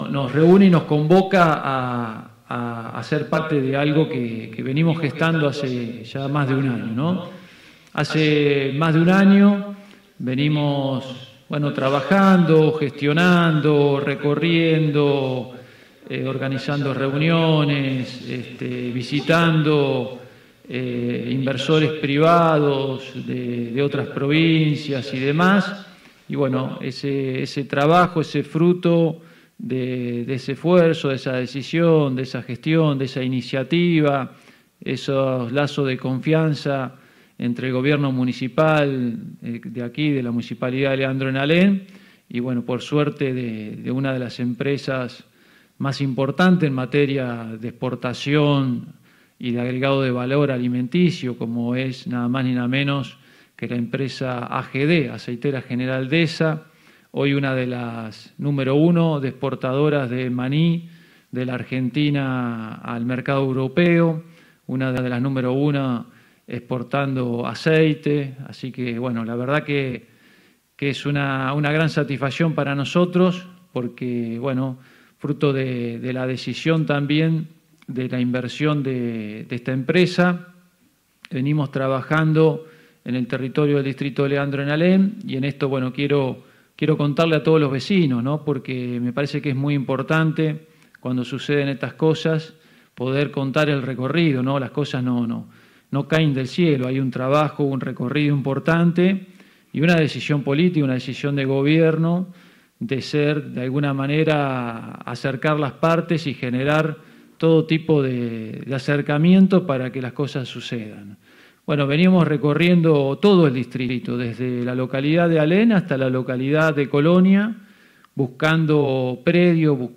Durante la jornada de este martes el Intendente Municipal, del distrito bonaerense de Leandro N. Alem, Carlos Ferraris, anuncio en una conferencia de prensa detalles oficiales de la primera planta de procesamiento de maní, el primero en su tipo en la provincia de Buenos Aires.